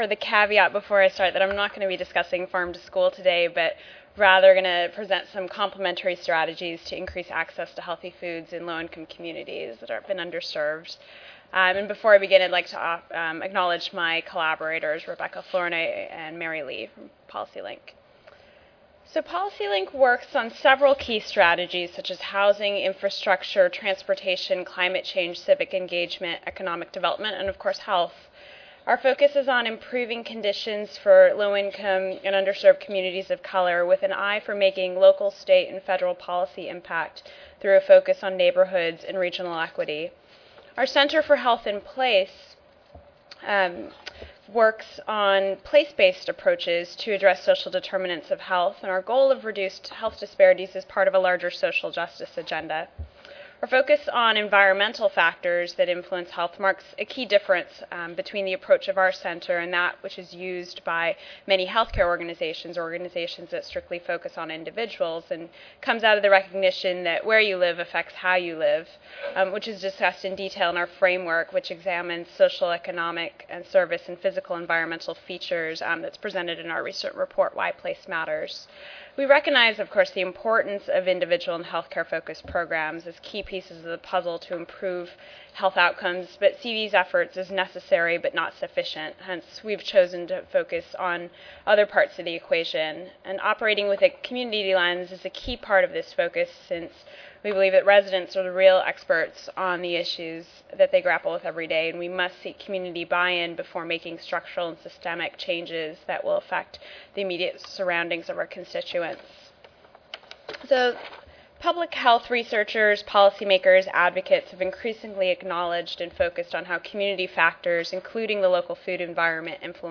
Audience members will be engaged in an interactive discussion of needed next steps in the growing movement to improve access to healthy food, including research needs, key partnerships, media outreach strategies, and promising policy opportunities.